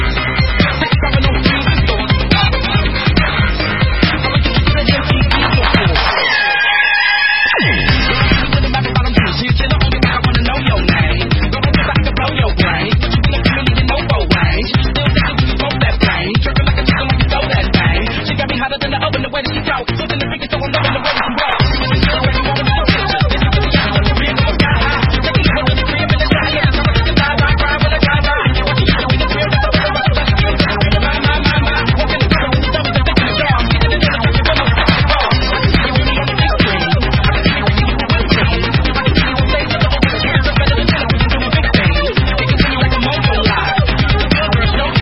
Зона Обмена | Музыка | Танцевальная